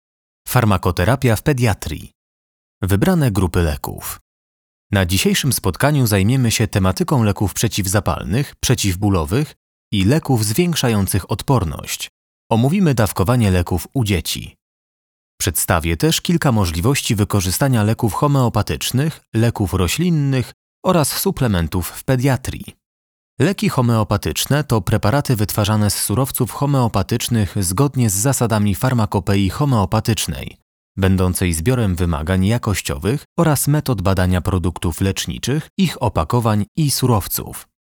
Male 30-50 lat
Young but mature voice whose versatile timbre works well for many types of recordings.
e-learning